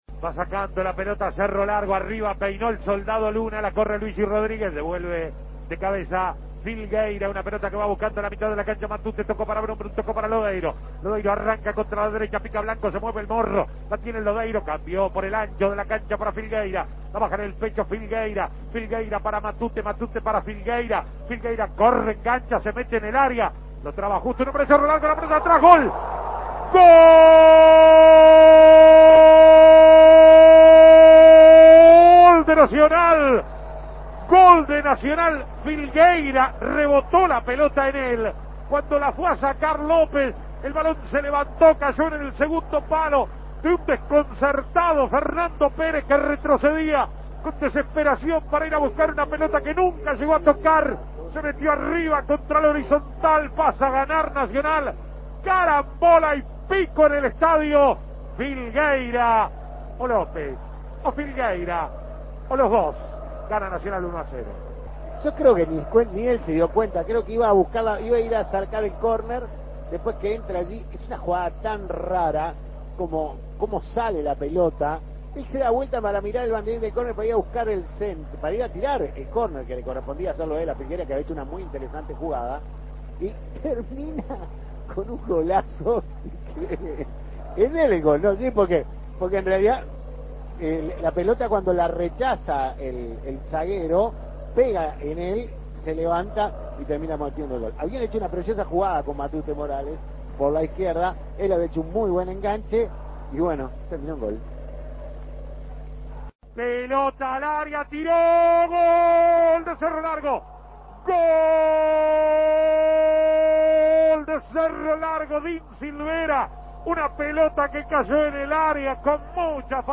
Escuche los goles del partido Nacional-Cerro Largo
Goles y comentarios